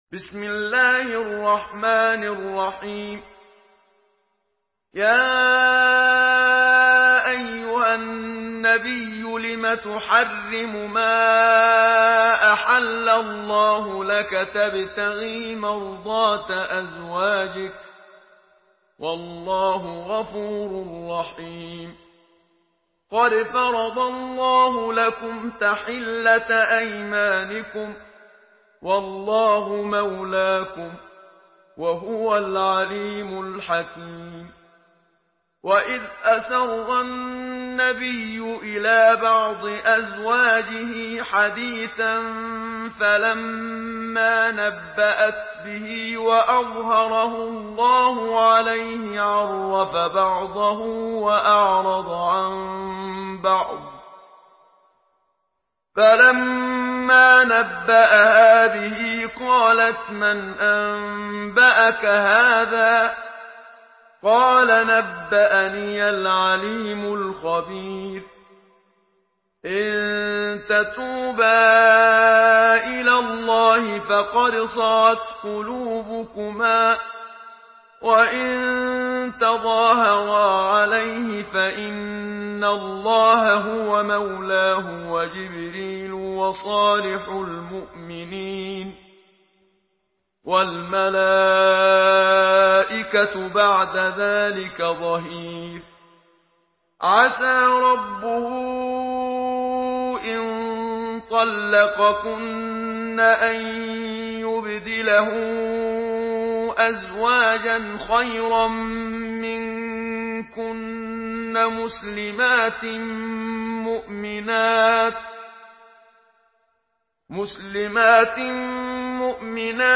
Surah At-Tahrim MP3 by Muhammad Siddiq Minshawi in Hafs An Asim narration.
Murattal